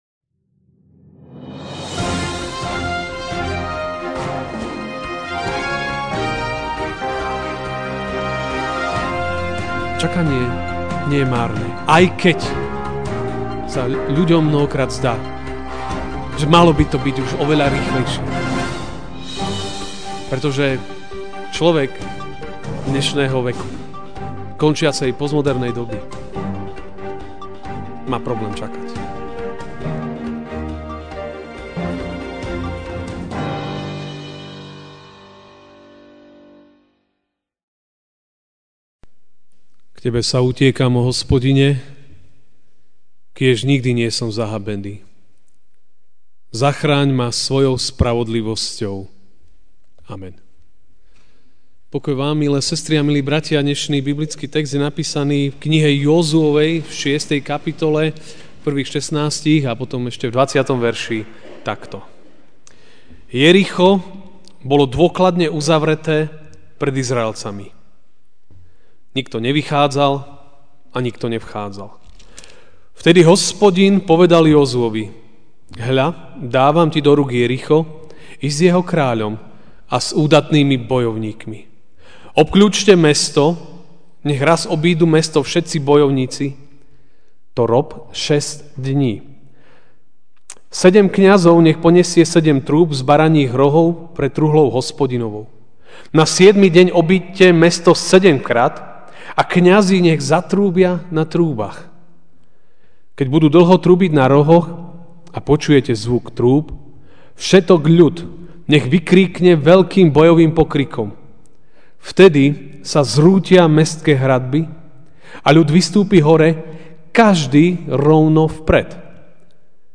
Ranná kázeň: Vytrvalá poslušnosť prináša výsledky (Jozue 6, 1-16 a 20) Jericho bolo dôkladne uzavreté pred Izraelcami.